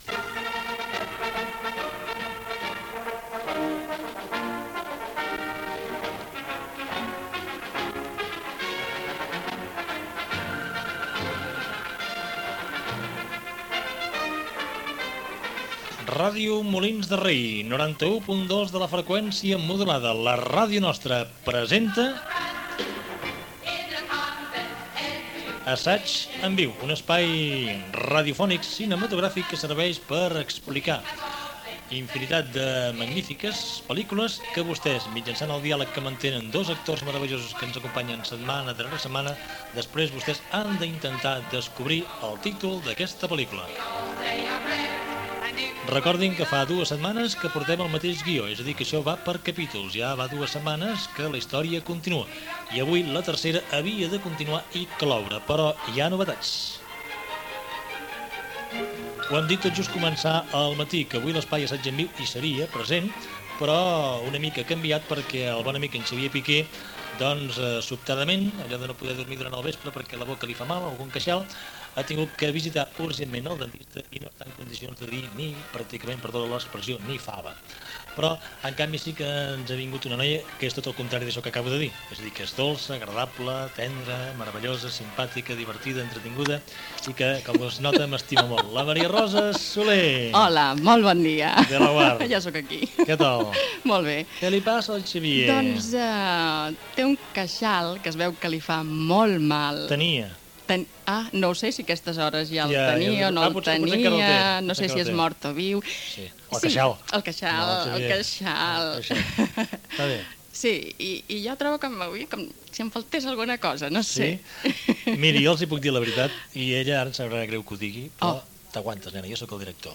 Espai "Assaig en viu" amb una ficció sonora cinematogràfica, trucada per encertar de quina pel·lícula es tractava i comiat del programa